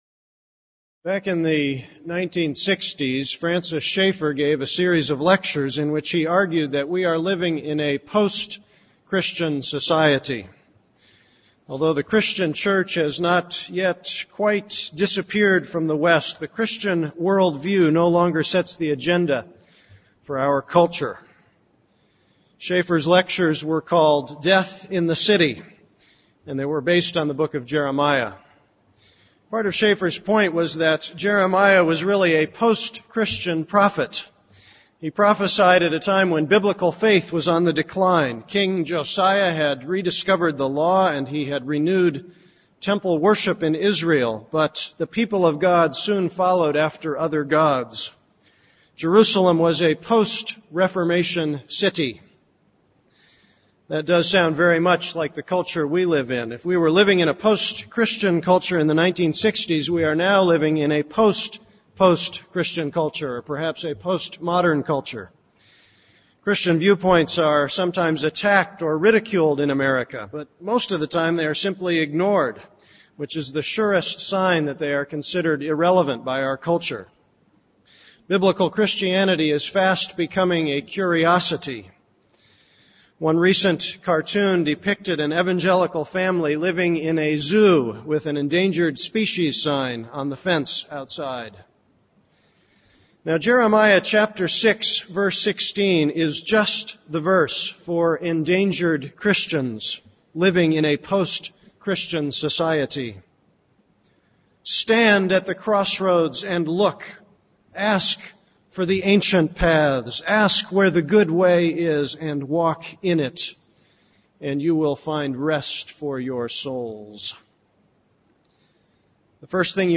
This is a sermon on Jeremiah 6:16-30.